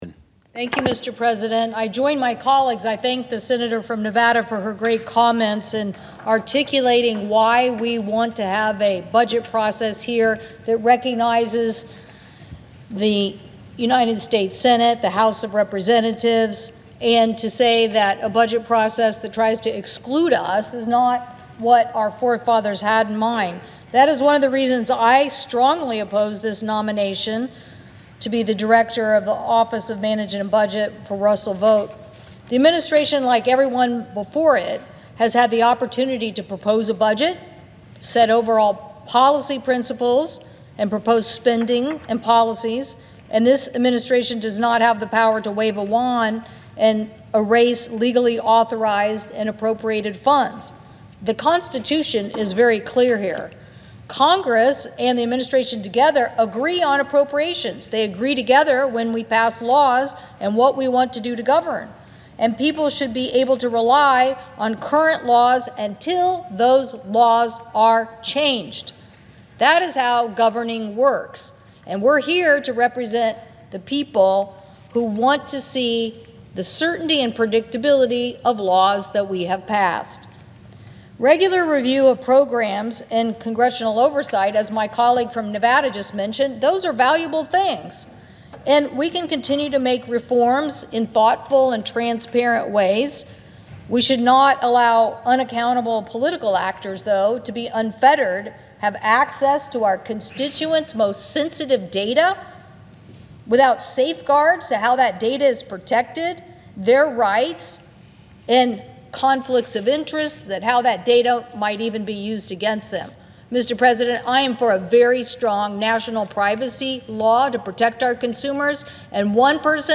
WASHINGTON, D.C. – Today, U.S. Senator Maria Cantwell (D-WA), senior member of the Senate Finance Committee and the ranking member of the Senate Committee on Commerce, Science, and Transportation, delivered a speech on the Senate floor calling on her colleagues to vote against confirming Russell T. Vought, President Donald Trump’s nominee to lead the Office of Management and Budget (OMB).